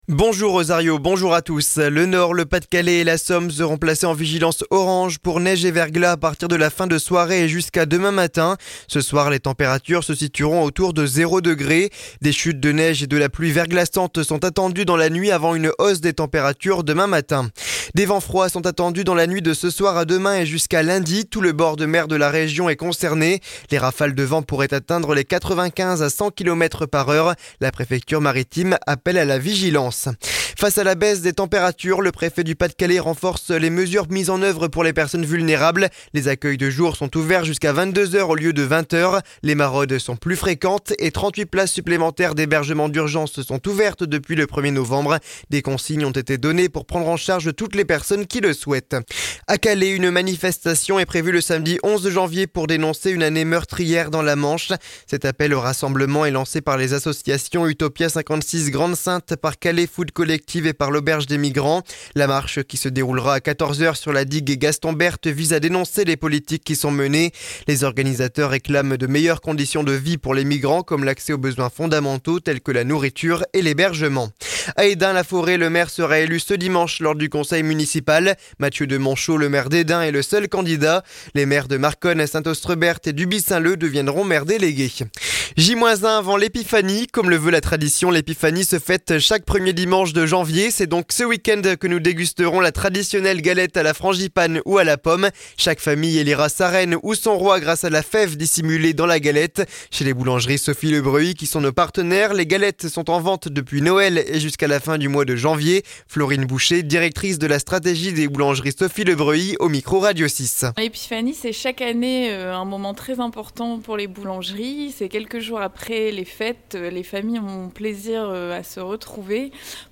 Le journal du samedi 04 janvier 2025.